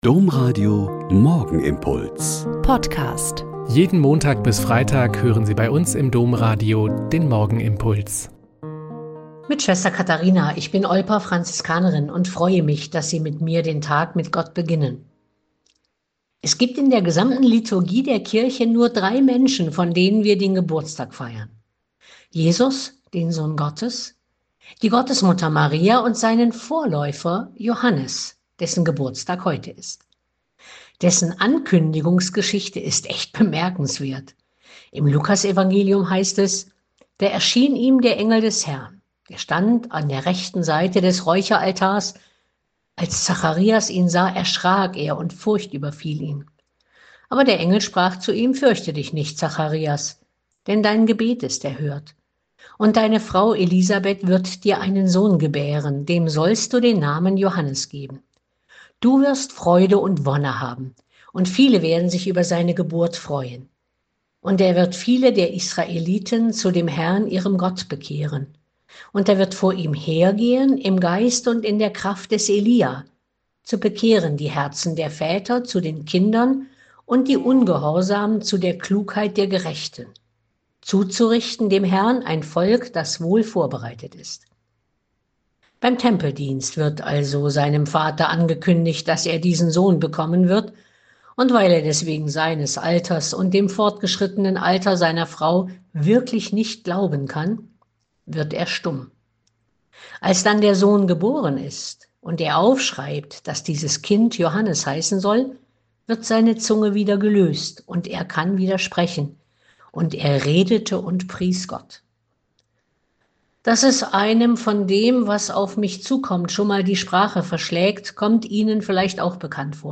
Lk 1, 57-66.80 - Gespärch